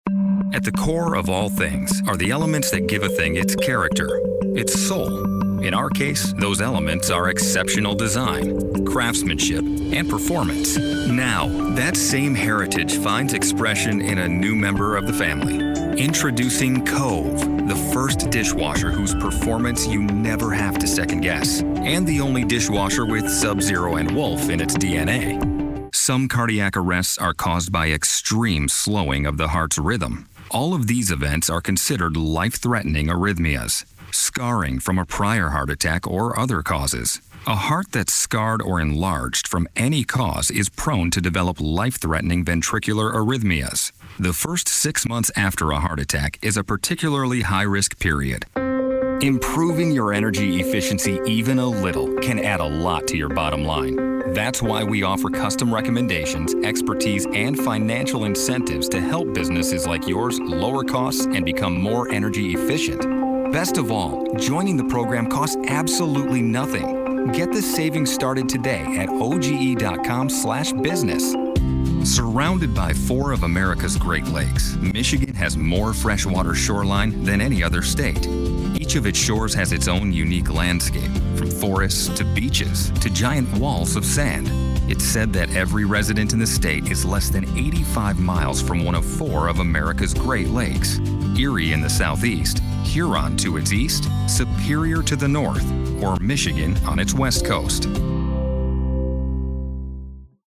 Guy-Next-Door, Upbeat, Friendly
Narration, Corporate, Documentary